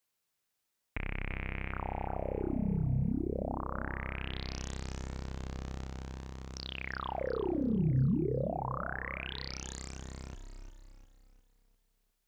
VIR-SAW-DRONE.wav